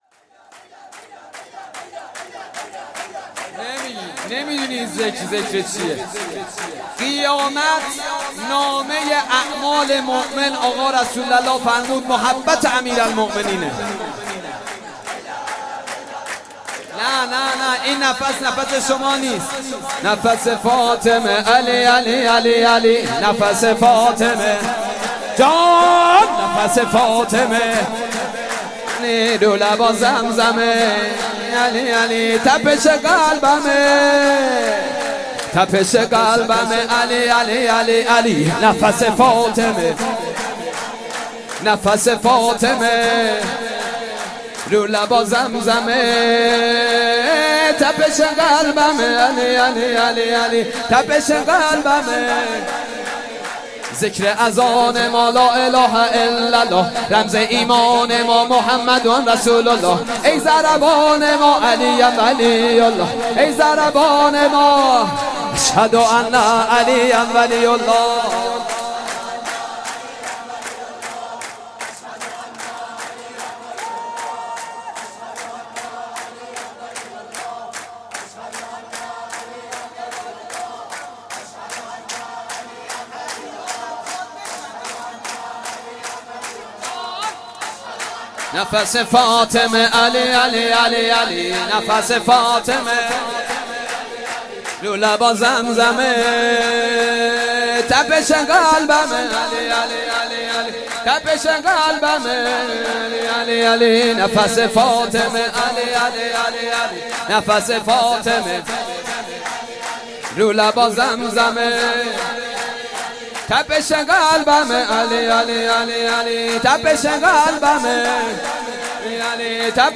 حسینیه بیت النبی